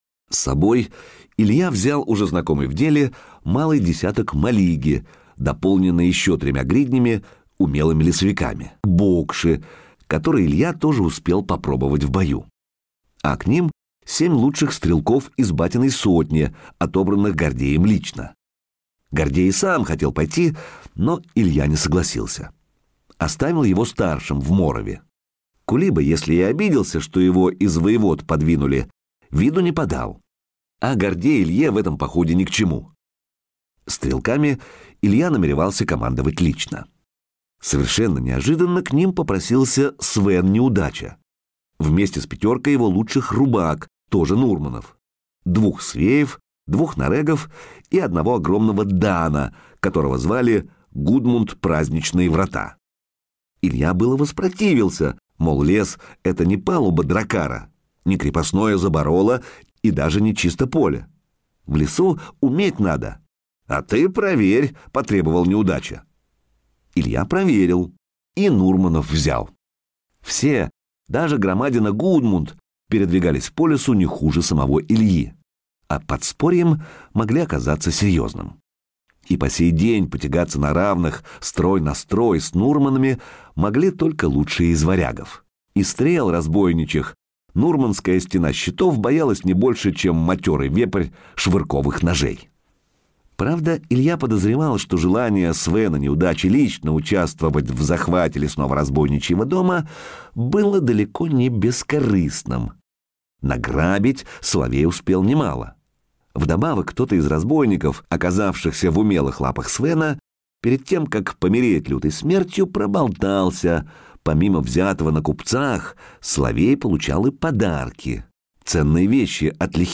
Аудиокнига Золото старых богов - купить, скачать и слушать онлайн | КнигоПоиск